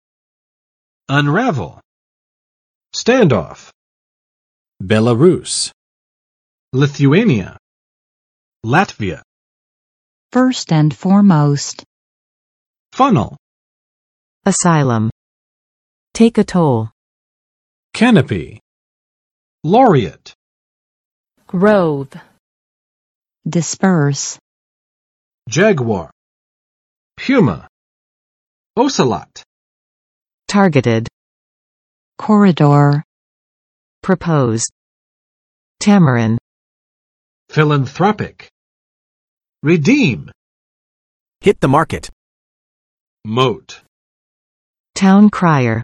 [ʌnˋræv!] v. 阐明；弄清